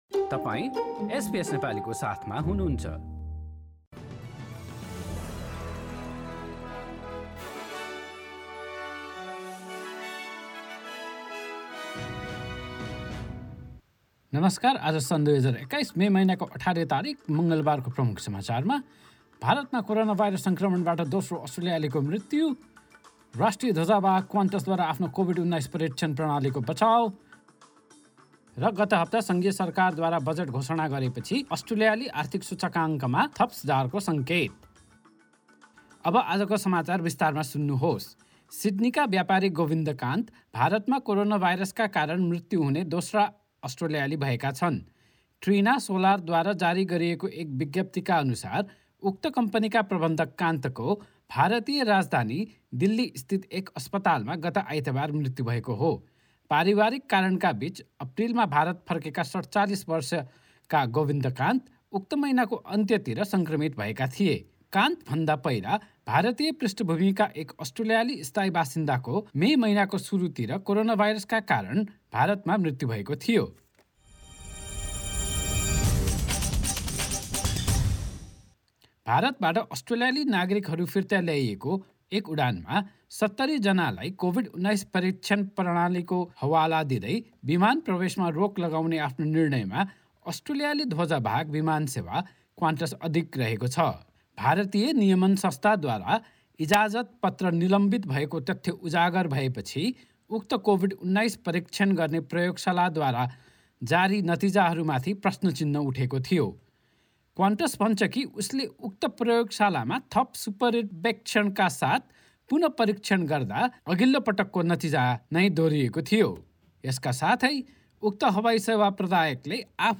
Listen to the latest news headlines from Australia in Nepali. In today's bulletin - a second Australian dies of COVID-19 in India, Qantas is standing by its COVID-19 testing process that resulted in 70 Australians being barred from last week's reparation flight from India and Federal Treasurer Josh Frydenberg says the big-spending budget is aimed at securing Australia's economic recovery and reducing unemployment.